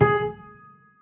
GSharp.wav